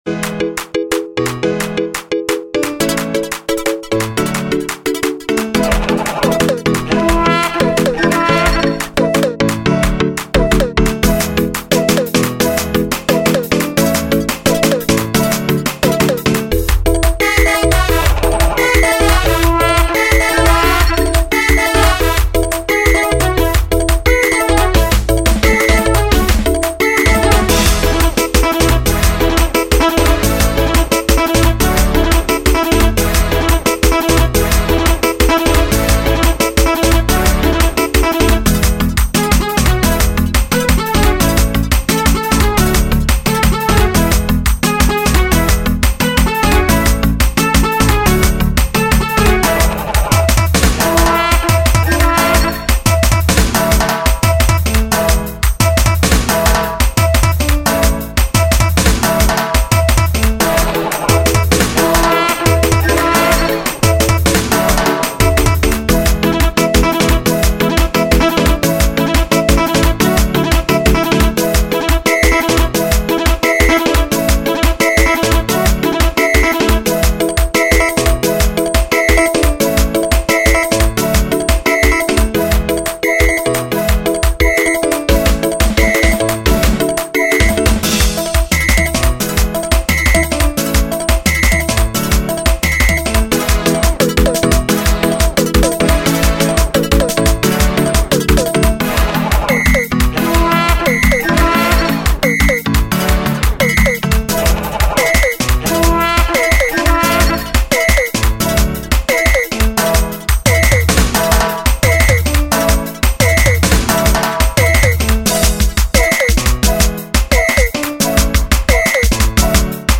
05:33 Genre : Xitsonga Size